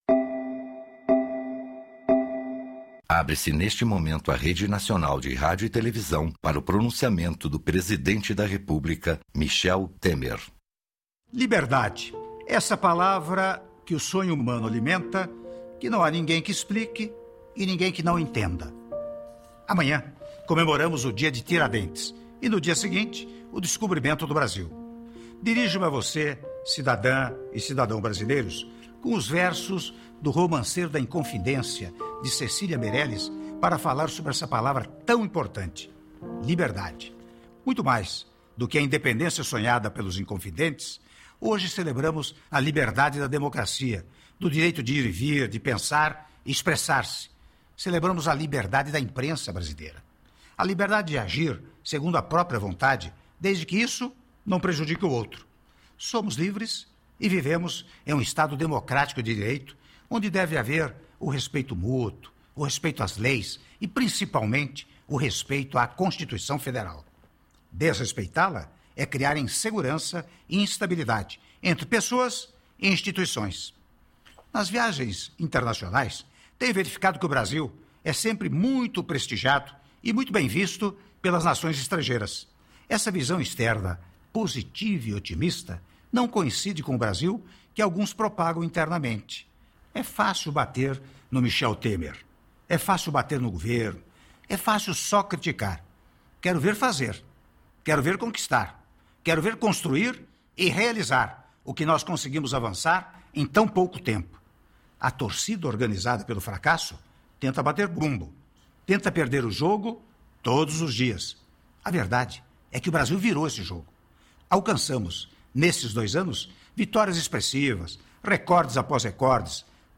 Pronunciamento do Presidente Michel Temer